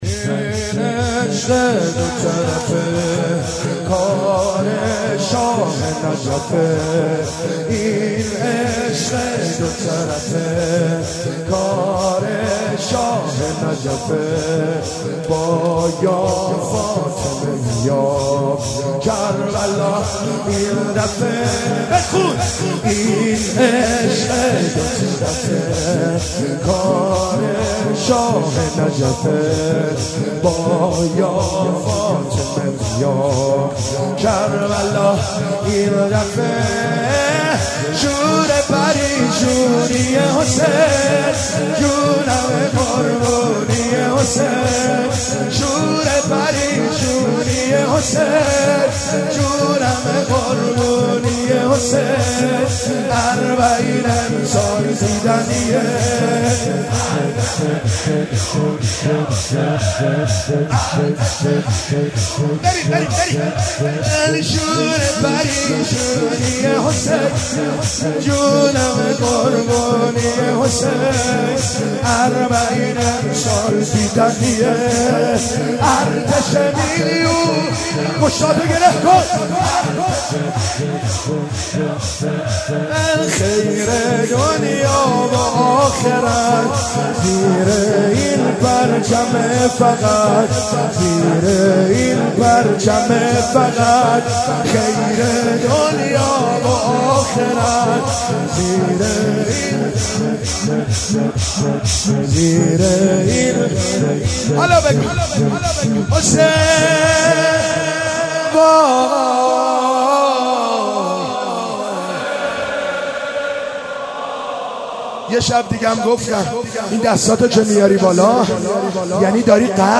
مناسبت : شب پنجم محرم
قالب : شور